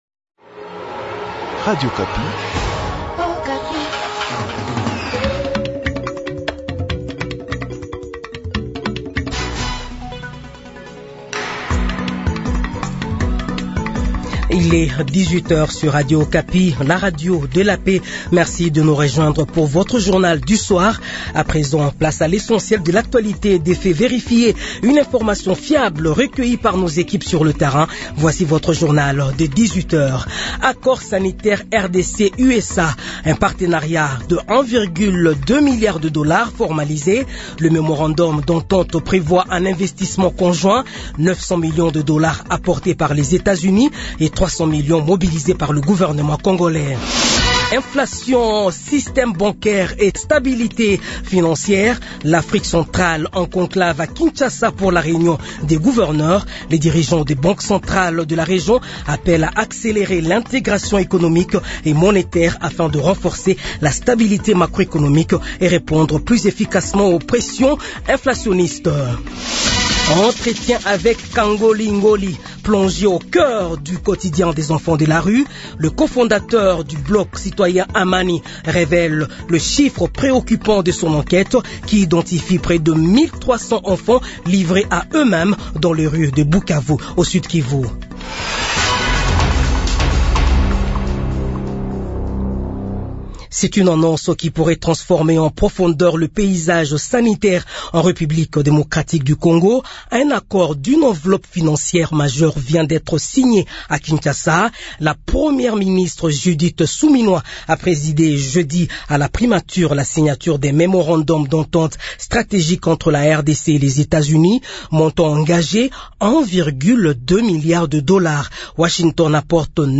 Journal Soir
Edition de 18 heures du samedi 28 février 2026